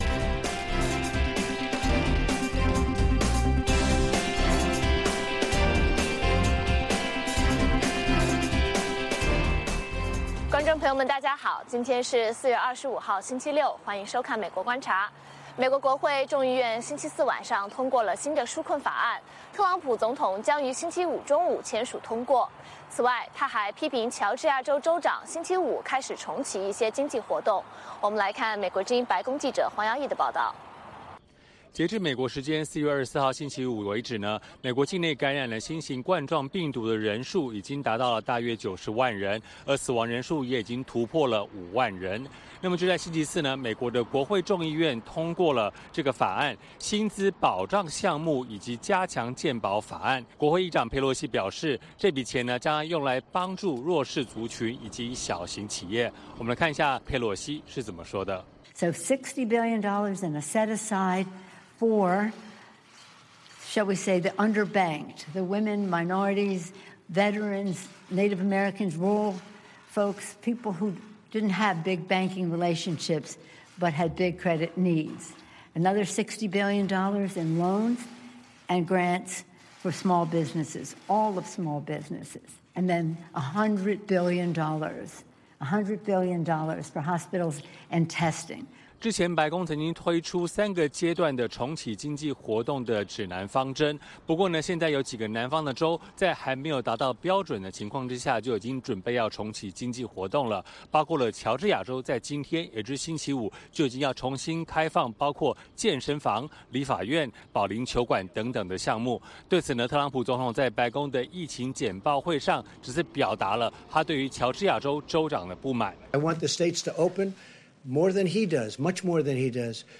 北京时间早上6-7点广播节目，电视、广播同步播出VOA卫视美国观察。 “VOA卫视 美国观察”掌握美国最重要的消息，深入解读美国选举，政治，经济，外交，人文，美中关系等全方位话题。节目邀请重量级嘉宾参与讨论。